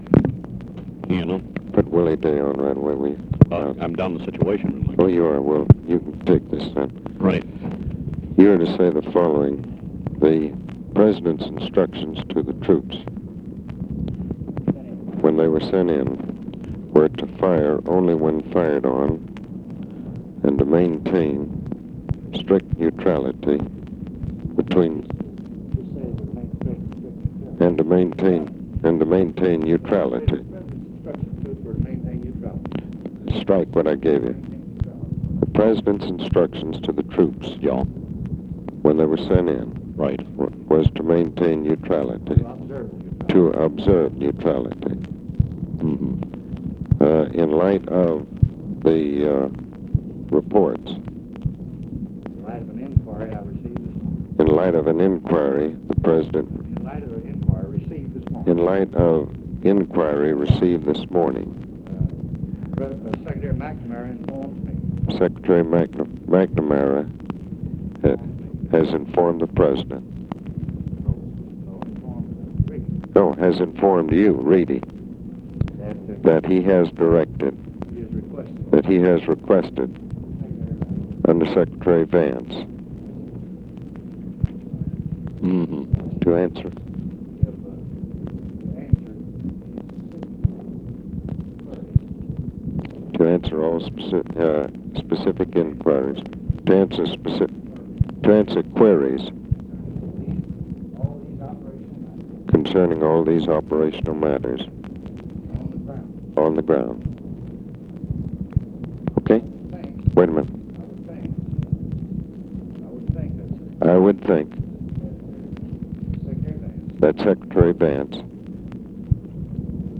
LBJ (HEARD IN THE BACKGROUND) DICTATES STATEMENT FOR PRESS ABOUT ALLEGED ACTIONS OF US TROOPS IN DOMINICAN REPUBLIC TO FORTAS WHO DICTATES IT TO REEDY; STATEMENT REPEATS LBJ'S STANDING ORDERS ON NEUTRALITY, REFERS QUESTIONS TO CYRUS VANCE
Conversation with ABE FORTAS, OFFICE CONVERSATION and GEORGE REEDY